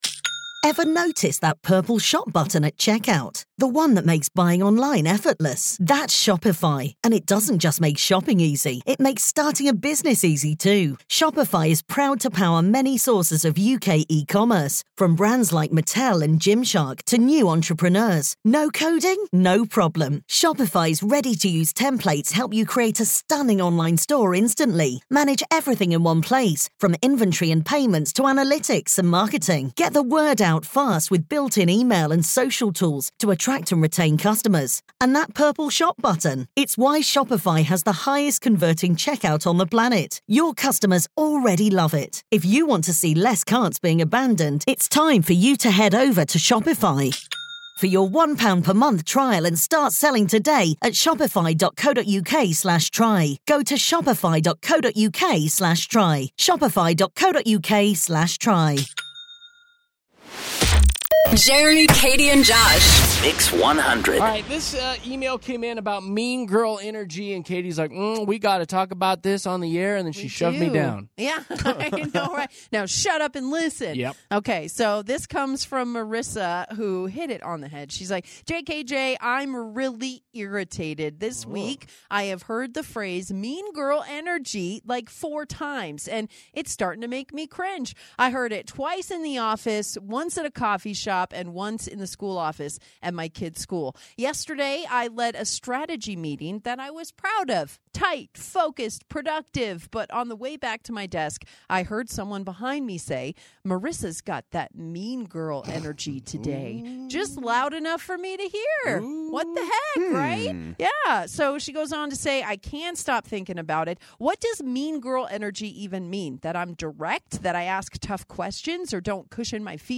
Therefore, we decided to explore her concerns further and opened the phone lines for you to share your insights on the concept of mean girl energy!